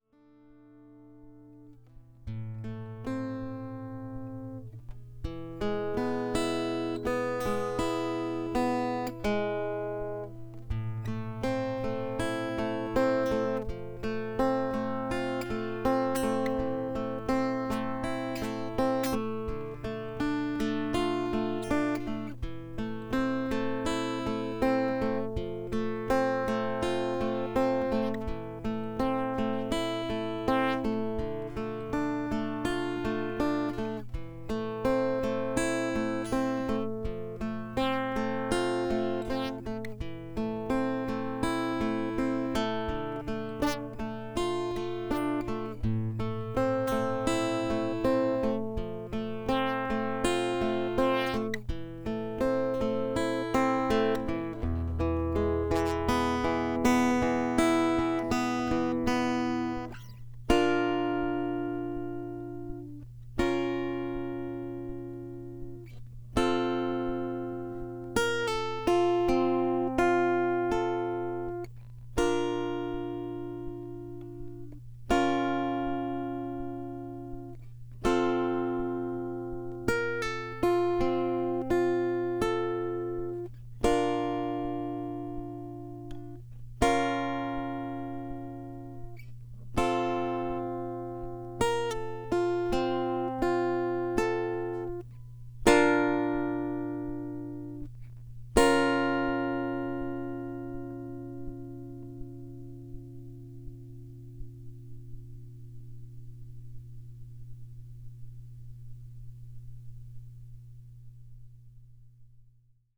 Electric Guitar Track with Noise.wav